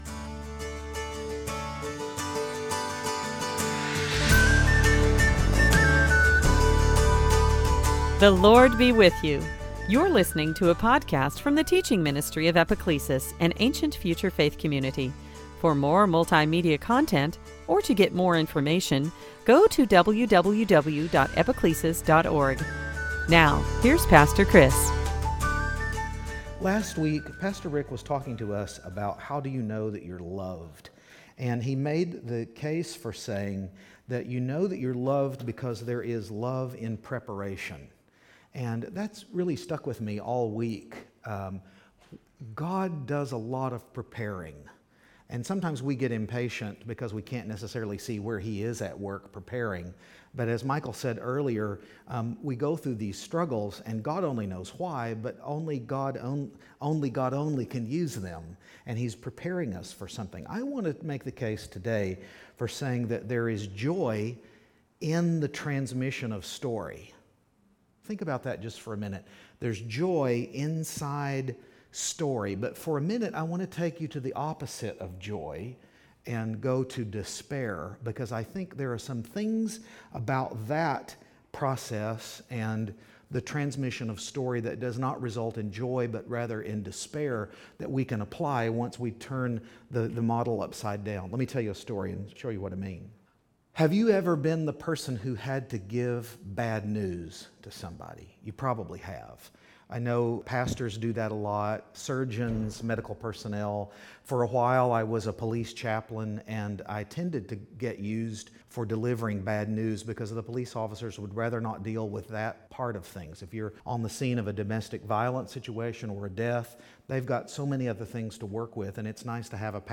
Service Type: Advent